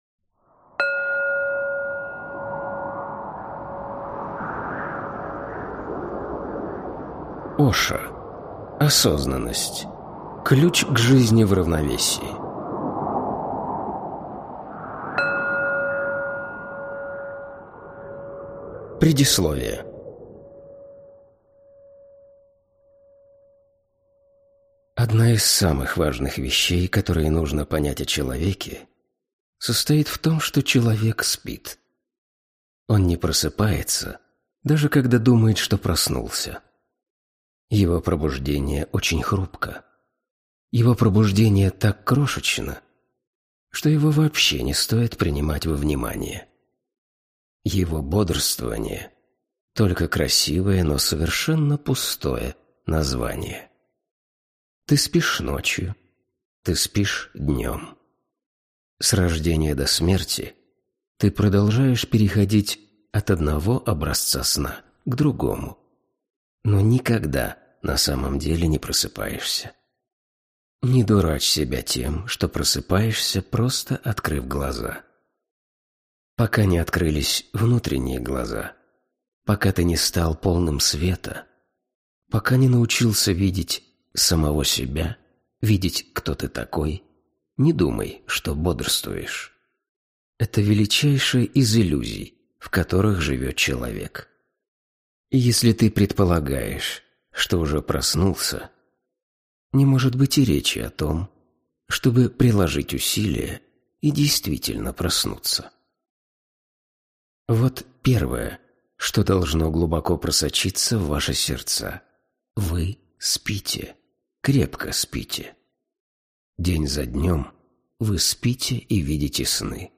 Аудиокнига Осознанность. Ключ к жизни в равновесии | Библиотека аудиокниг